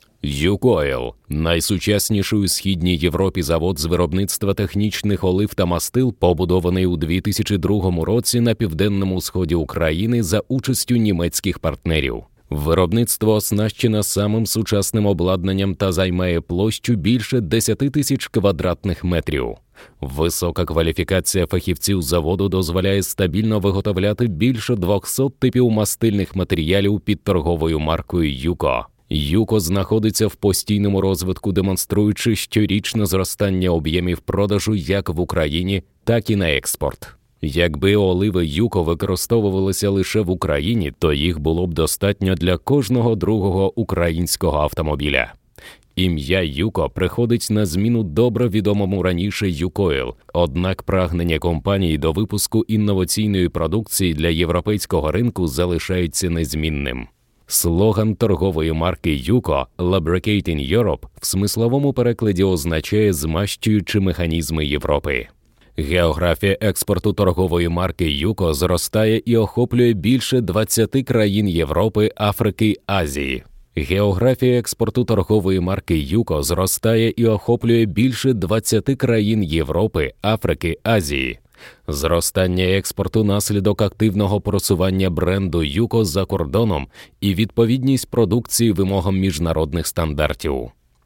Yabancı Seslendirme Kadrosu, yabancı sesler